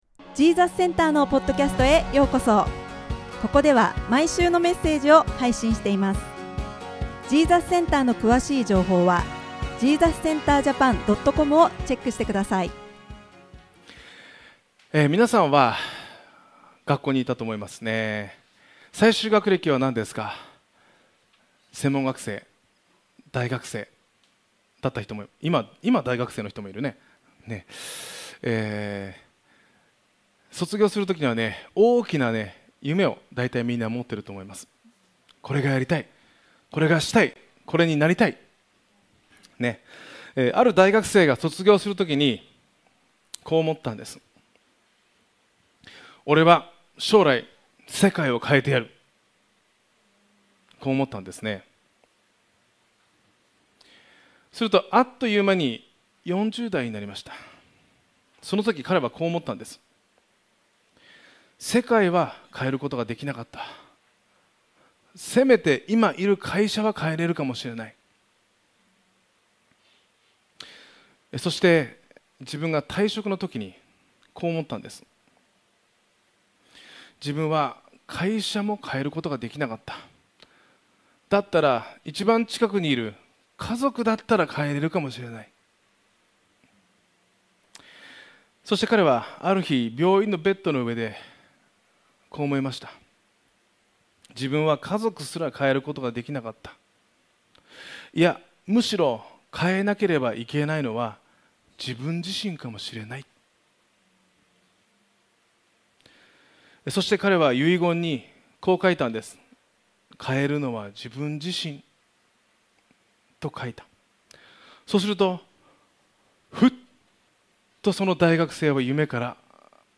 ２ペテロ１：１４ 14 それは、私たちの主イエス・キリストも、私にはっきりお示しになったとおり、私が ジーザスセンターの聖書のメッセージです。